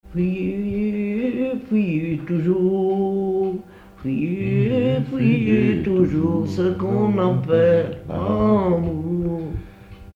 Pièce musicale inédite